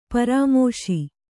♪ parāmōśi